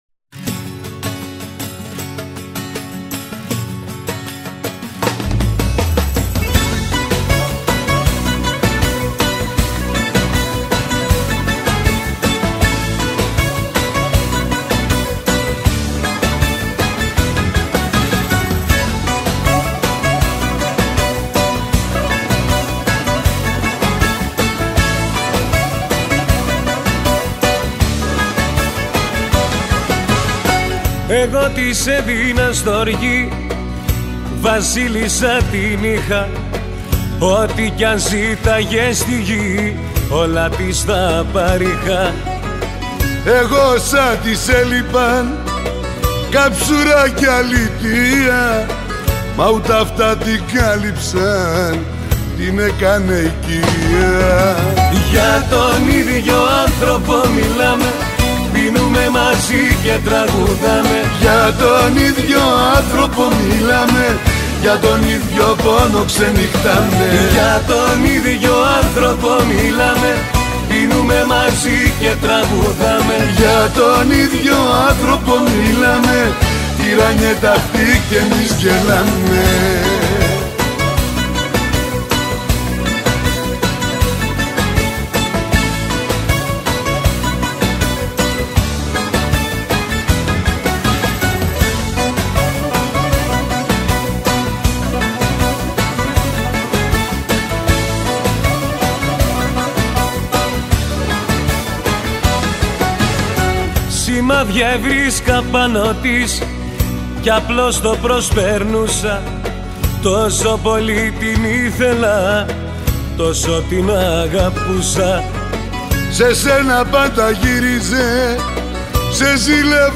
اهنگ ریتمیک رو که گفته بودم فردا میذارم.